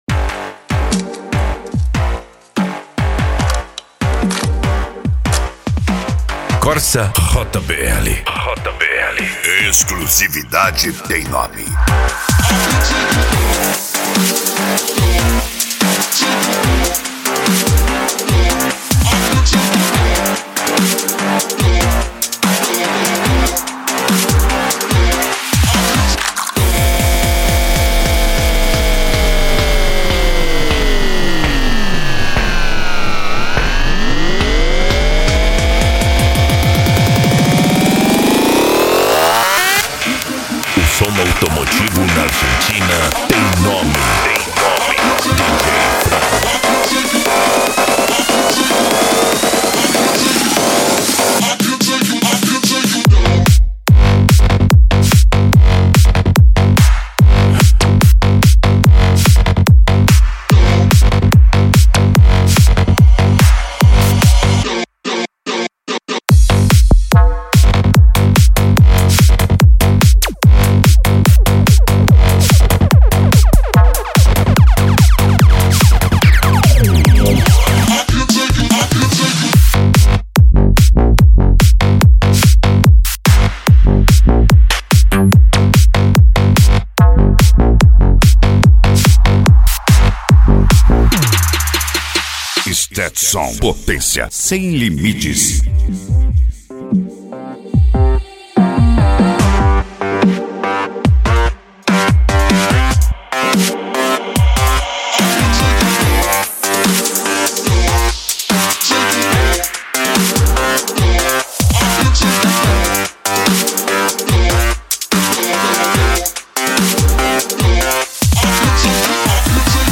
Eletronica
PANCADÃO
Psy Trance